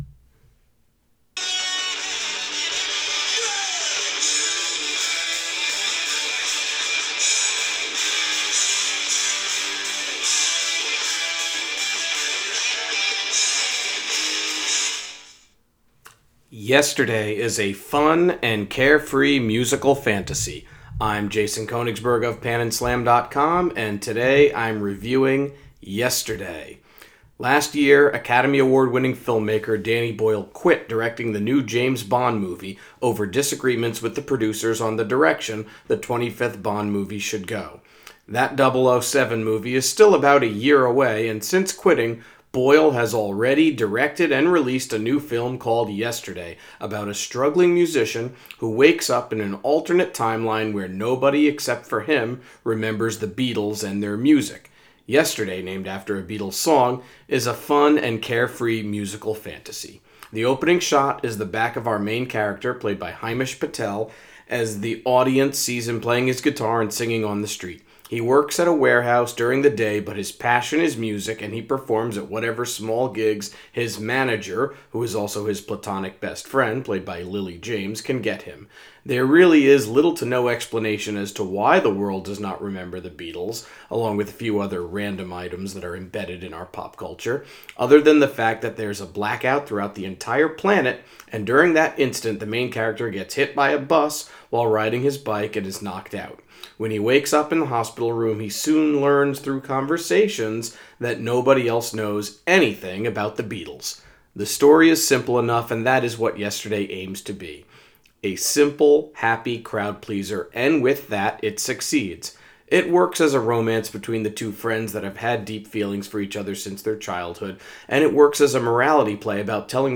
Movie Review: Yesterday